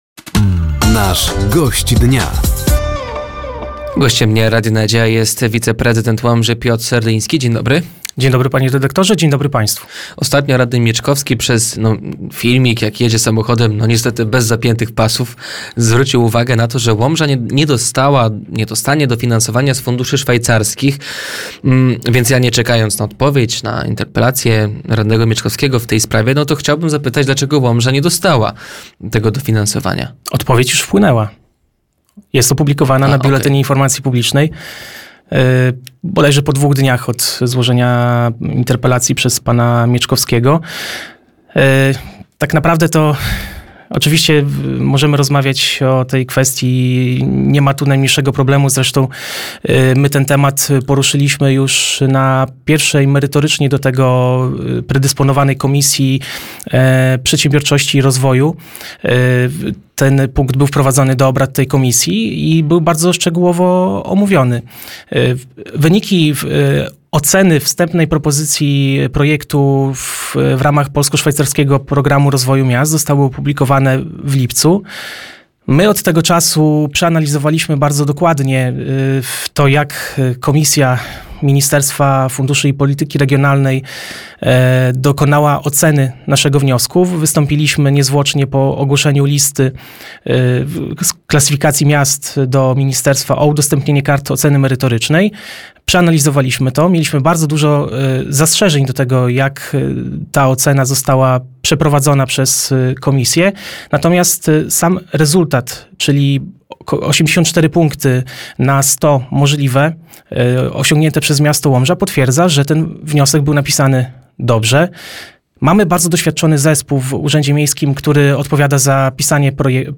Gościem Dnia Radia Nadzieja był wiceprezydent Łomży Piotr Serdyński. Tematem rozmowy były Fundusze Szwajcarskie, do których Łomża się nie zakwalifikowała, budowa hali przy SP9, kolejny przetarg na tężnię w parku Jana Pawła II oraz druga rocznica wyborów parlamentarnych.